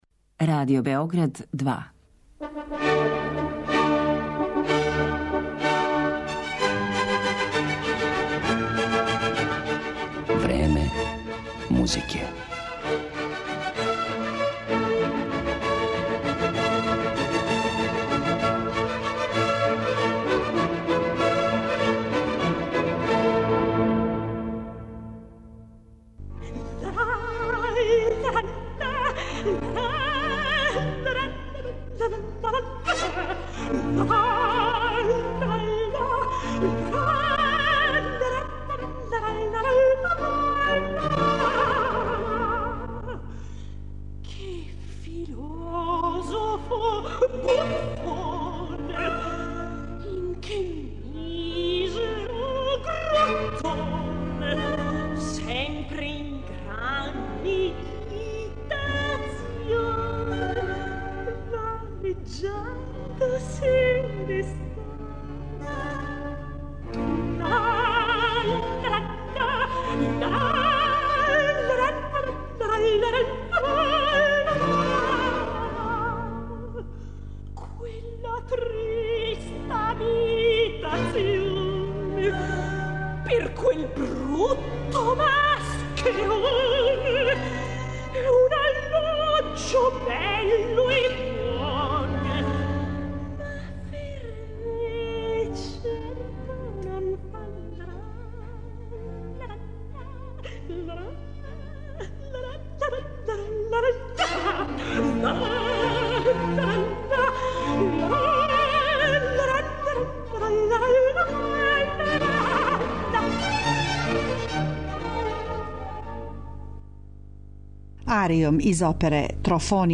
Осим арија из његових опера и одломака симфонијских остварења